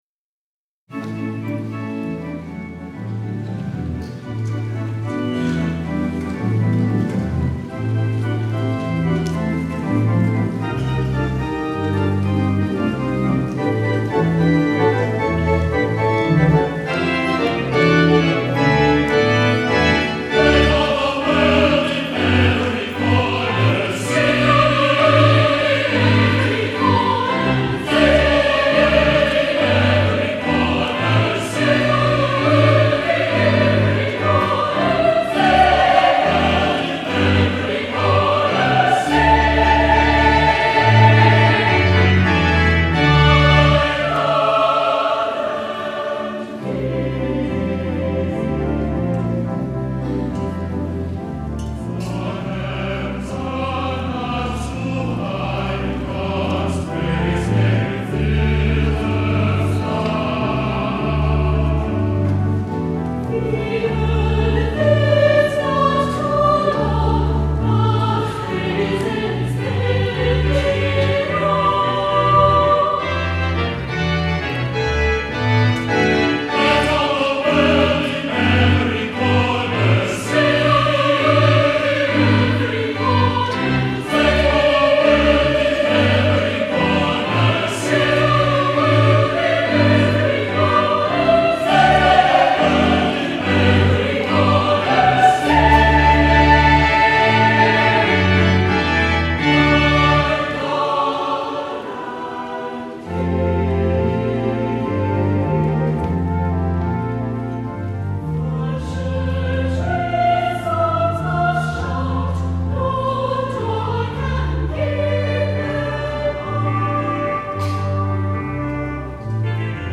Voicing: SATB divisi and Organ